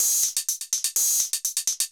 UHH_ElectroHatC_125-03.wav